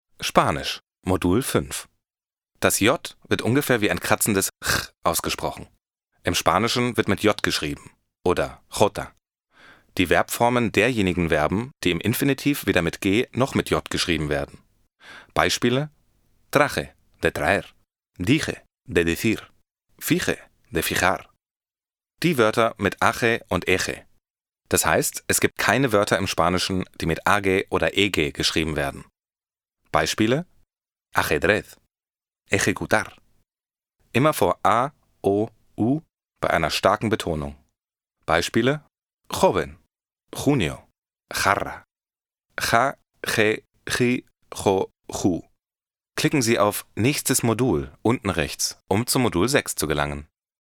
Warm, dynamic, friendly, versatile, corporate, commercial and multilingual fresh voice to make your production shine!
Sprechprobe: eLearning (Muttersprache):